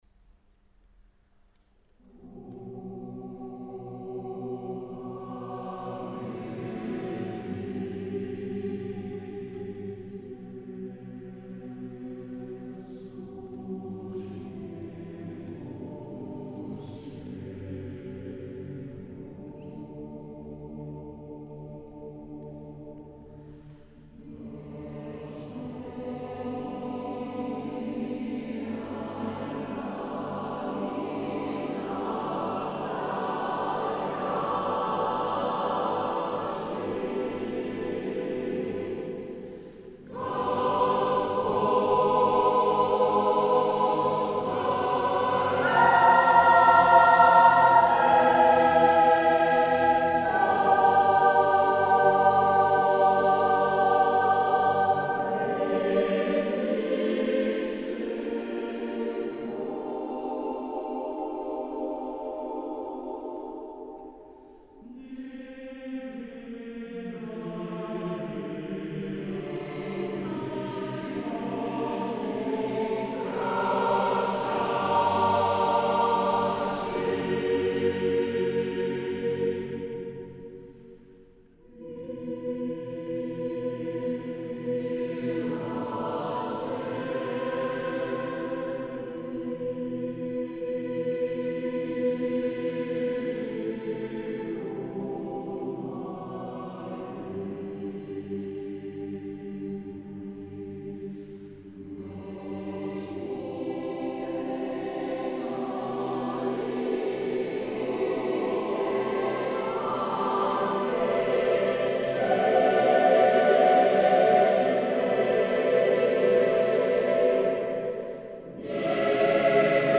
Musica Vocale & het ONK in de Pieterskerk Utrecht (23 maart 2018)
Het was zo’n concert waarbij alles op zijn plek viel: de sfeer van de kerk, de energie in het koor (beide koren!) en de gezamenlijke concentratie die van de muziek een bijzondere beleving maakte.